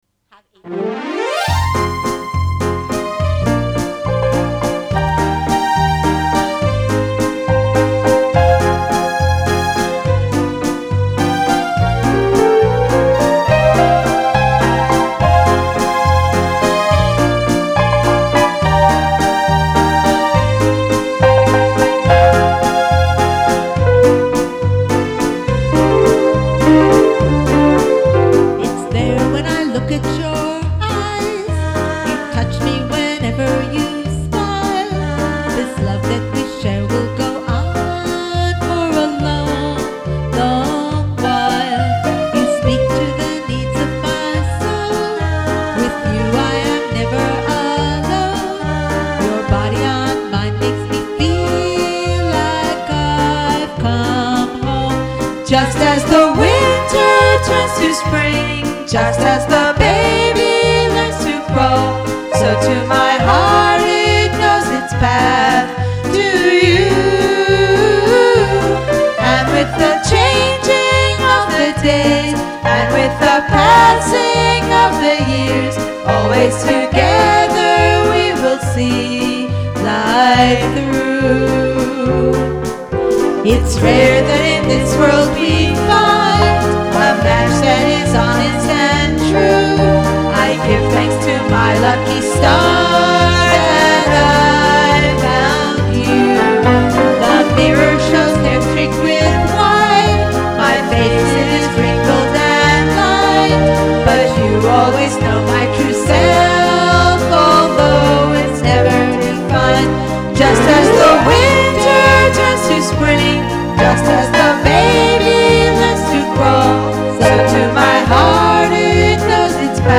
This song was recorded totally at home.